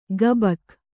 ♪ gabak